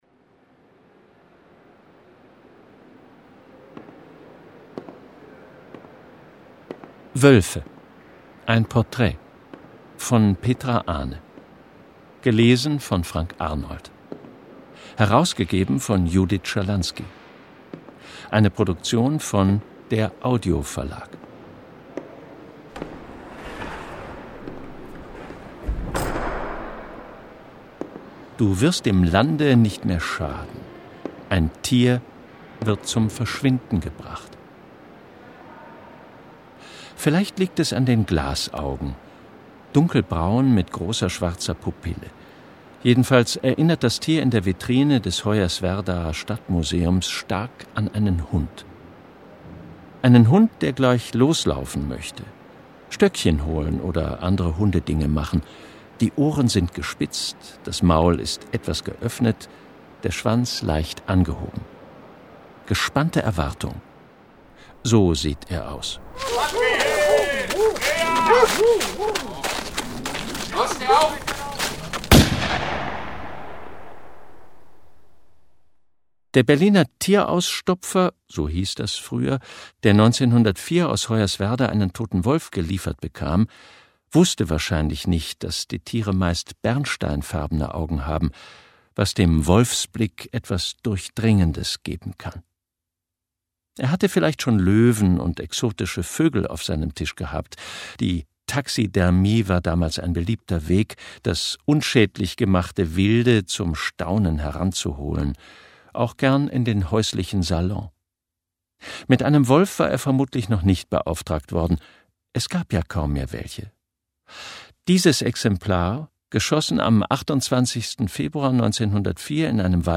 Schlagworte Judith Schalansky • krähen • Naturkunden • Natur Writing • Neuerscheinung 2021 • Peter und der Wolf • Reihe • Rotkäppchen • Rückkehr • Sachhörbuch